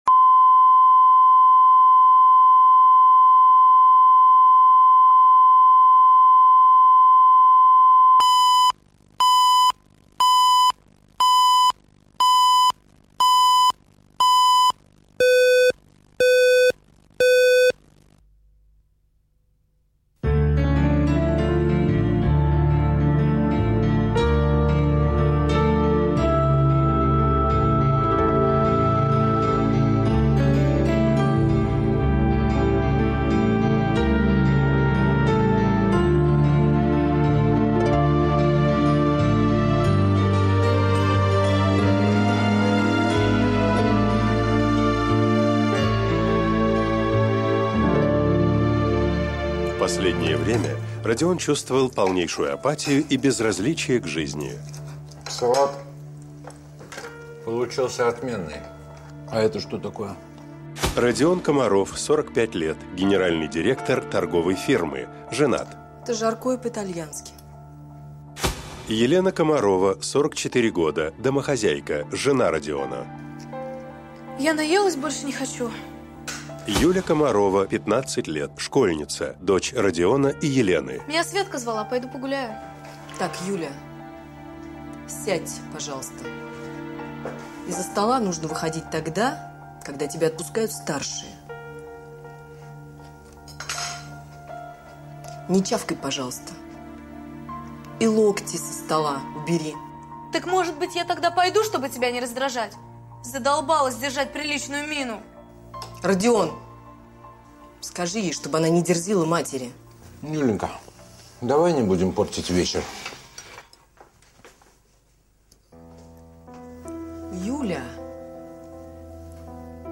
Аудиокнига Бунт